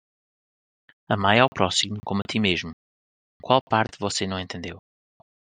(IPA) olarak telaffuz edilir /ˈt͡ʃi/